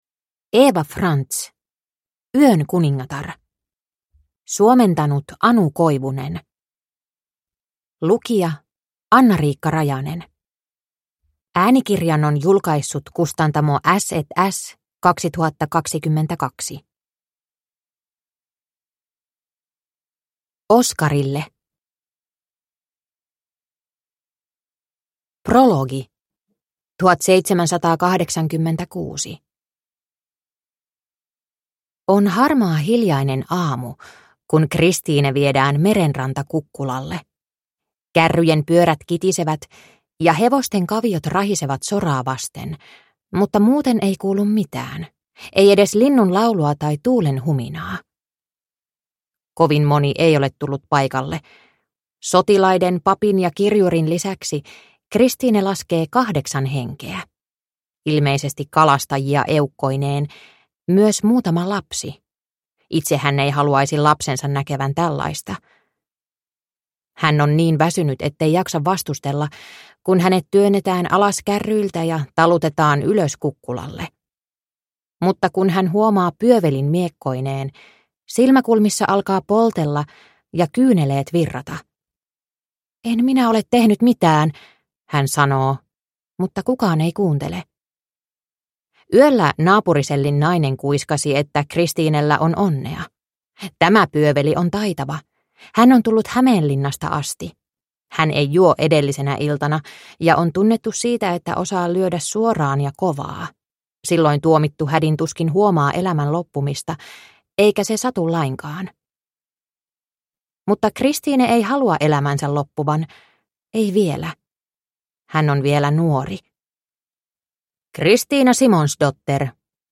Yön kuningatar – Ljudbok